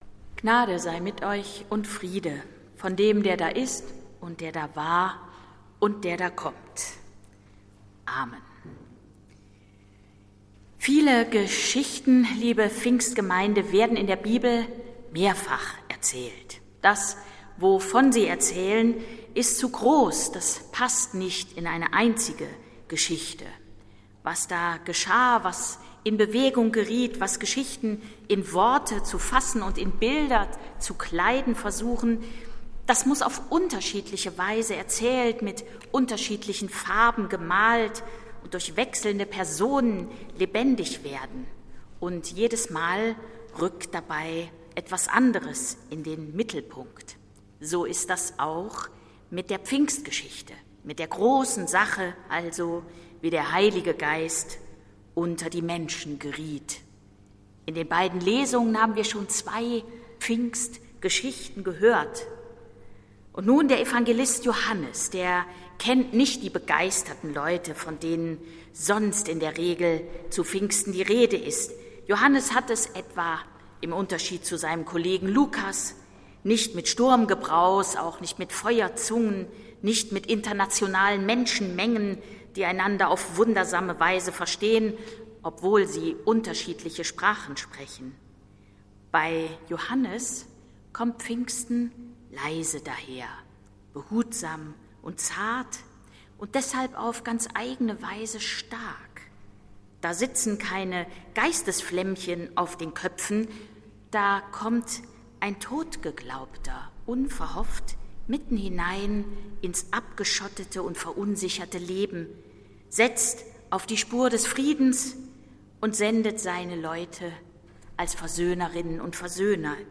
Predigt des Gottesdienstes aus der Zionskirche vom Pfingstmontag, 24.05.2021
Die Predigt hielt die Präses der Evangelischen Kirche von Westfalen, Annette Kurschus.